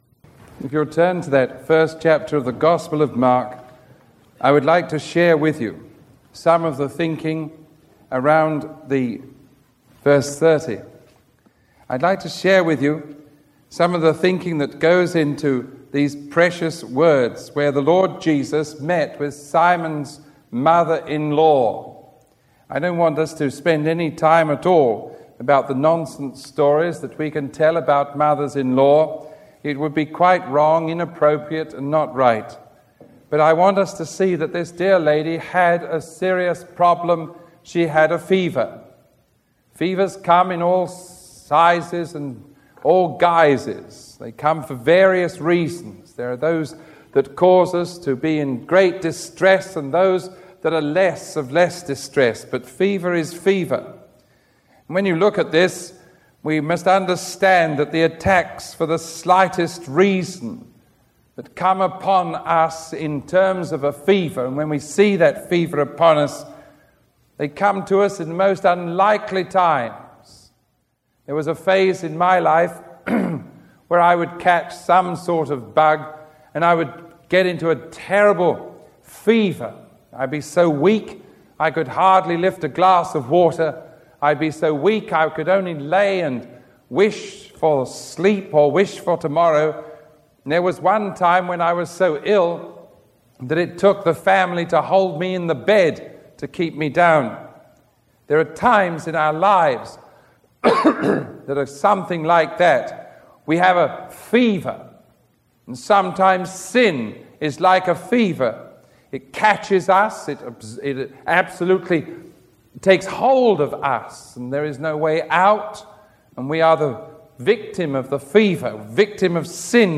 Sermon 1085B recorded on May 11, 1986 teaching from Mark 1:21-45 – Sick of a Fever.